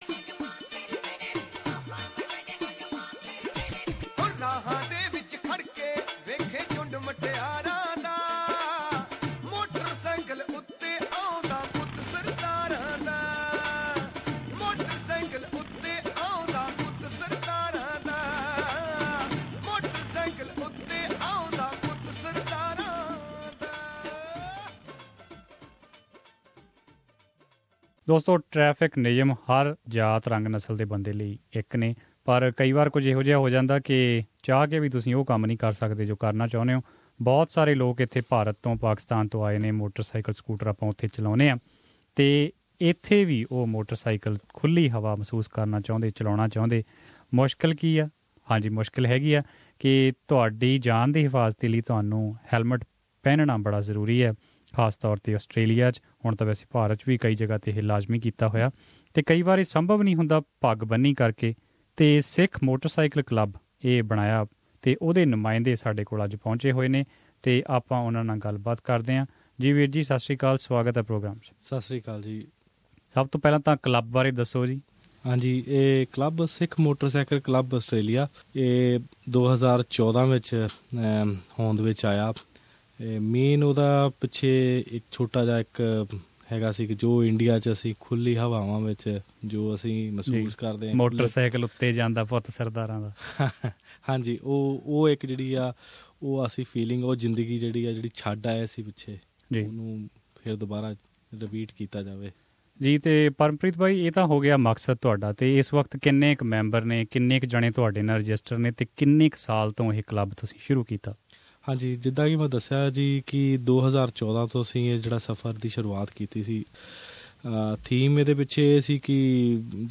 Here we have a conversation